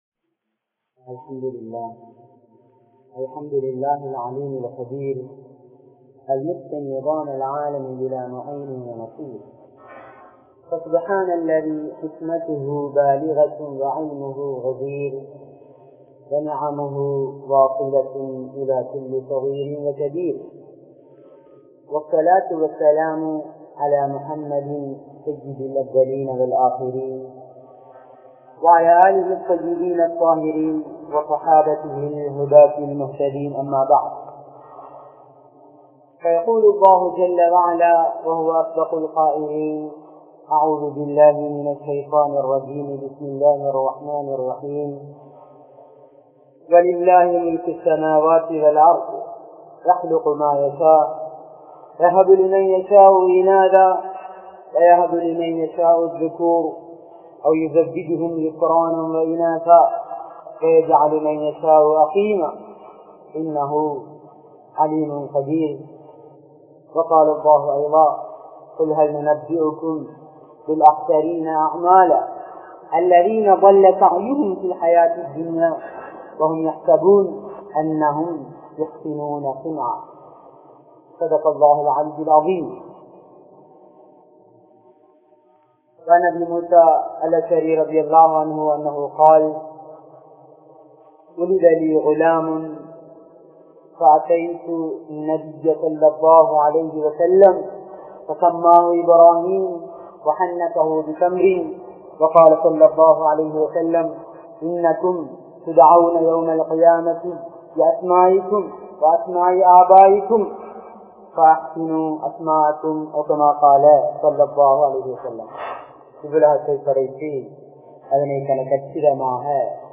Petroarhalin Kadamaihal (பெற்றோர்களின் கடமைகள்) | Audio Bayans | All Ceylon Muslim Youth Community | Addalaichenai
Majmaulkareeb Jumuah Masjith